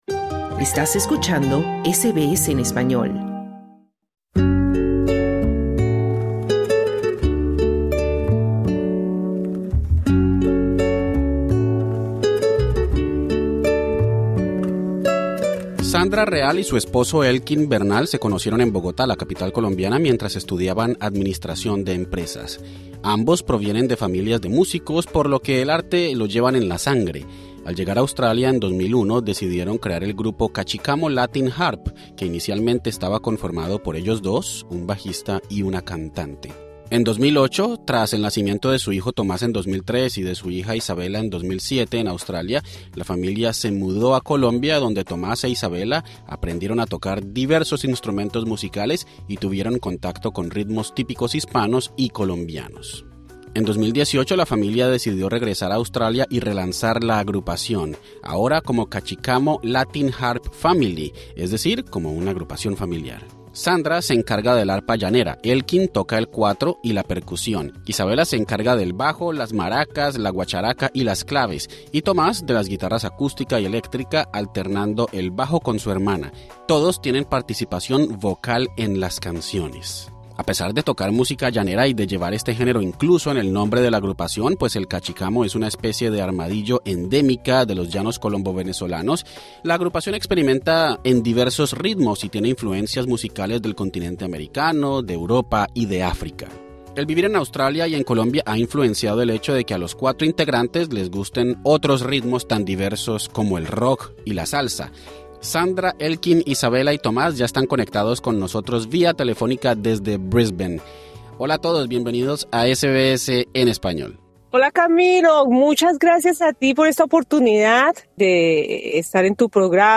Conversamos con la agrupación musical de origen colombiano, Cachicamo Latin Harp Family, integrada por padre, madre, hijo e hija. Viven en Brisbane y tienen influencias musicales diversas como los ritmos de los llanos colombo-venezolanos, la cumbia, el rock y la salsa. Conoce su historia y escucha sus canciones originales.
Además, todos cantan.